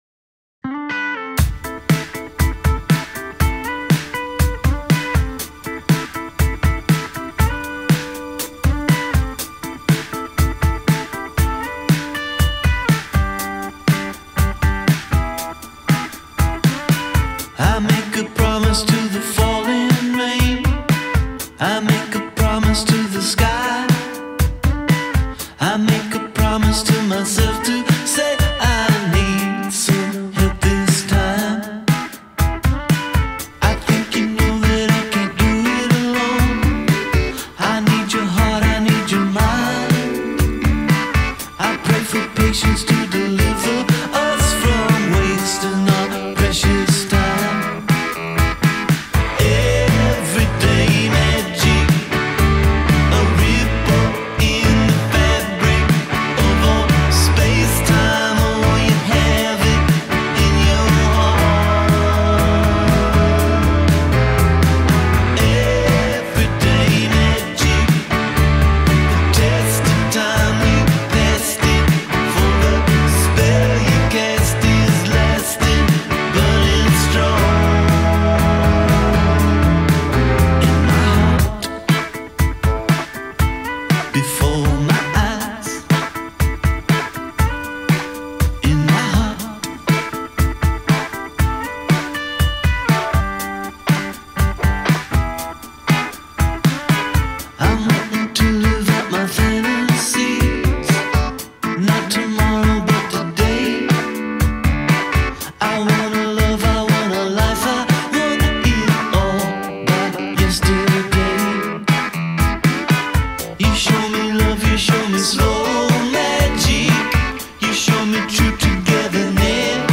It just builds and lets you come to it.